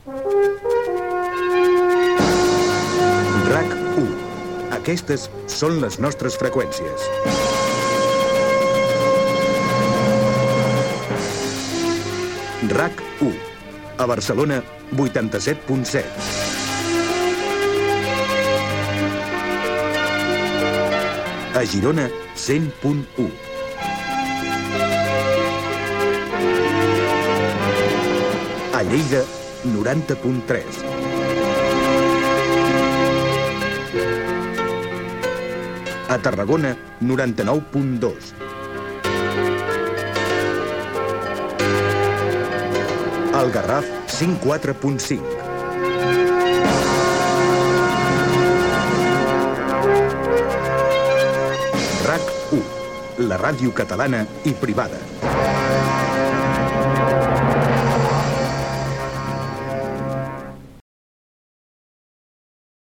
86865fca68d88cfae8db85fe264a688de1e241ad.mp3 Títol RAC 1 Emissora RAC 1 Barcelona Cadena RAC Titularitat Privada nacional Descripció Freqüències de l'emissora dins del programa especial d'inauguració de l'emissora.